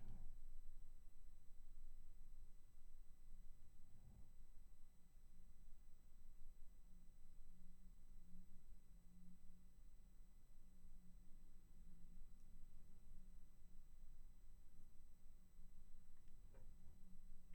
Neue Lüfter eingebaut - aber trotzdem ist ein Rauschen zu vernehmen
Habt Ihr erkannt, was das Fiepen auf meiner Aufnahme sein könnte? Anhänge Fiepen.wav Fiepen.wav 3 MB